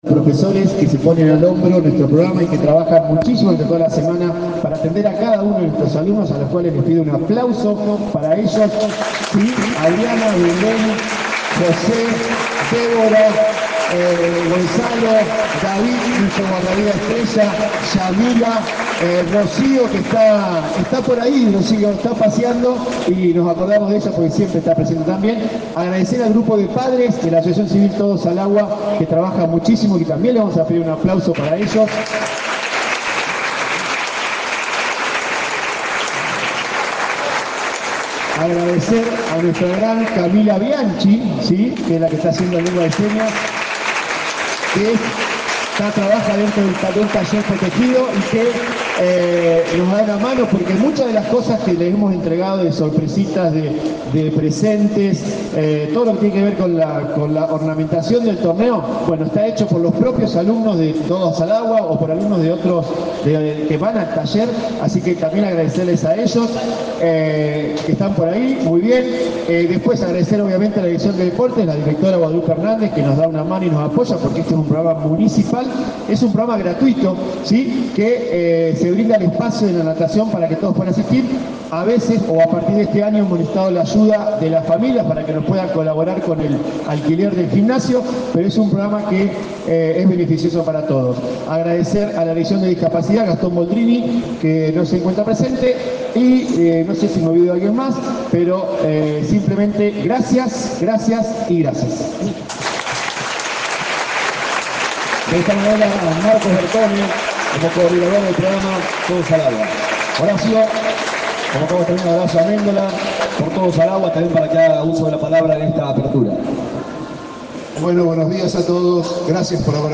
El acto central se realizó en el natatorio Palestra